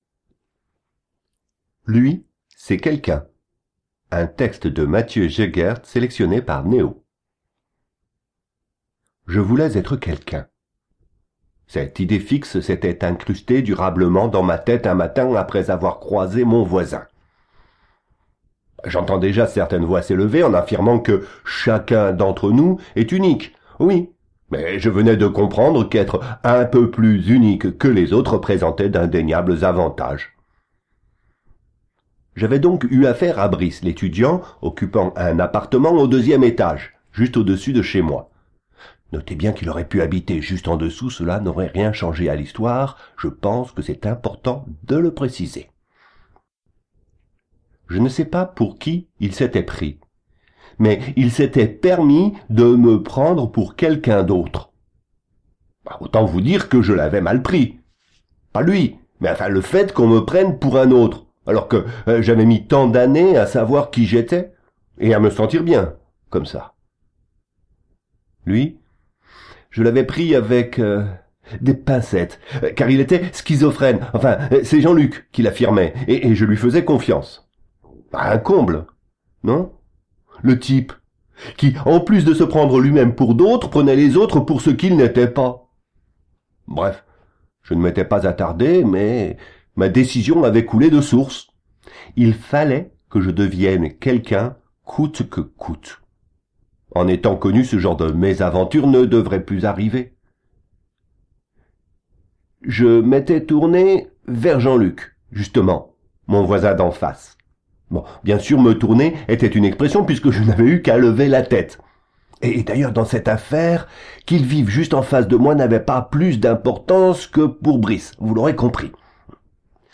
Aucune fausse note, un vrai « jeu d’acteur ». Très pro.